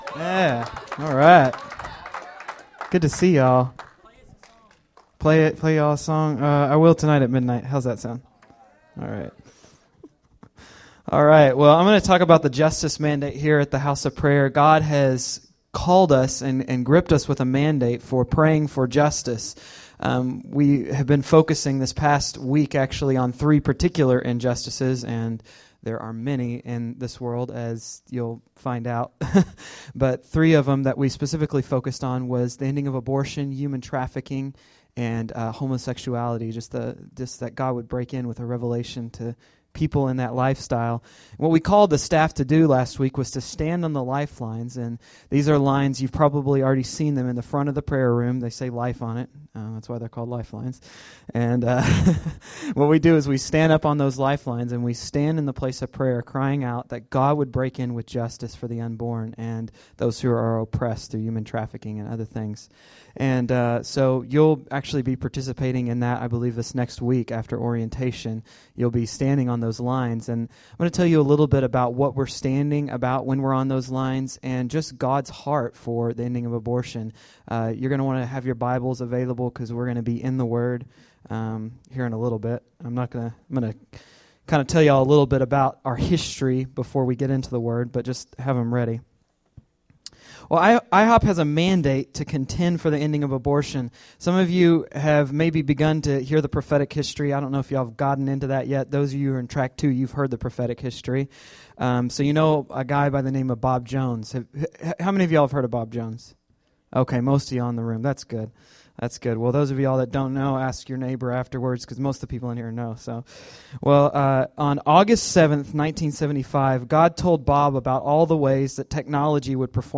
Justice-Teaching-Fire-in-the-Night.mp3